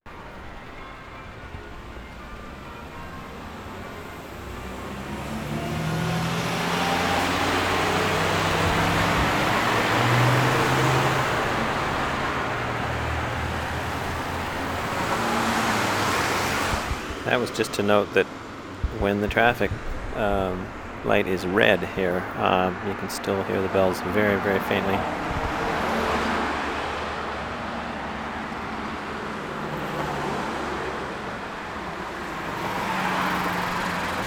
HOLY ROSARY CATHEDRAL
bells and traffic 0:33
14. short take, mostly traffic, tape ID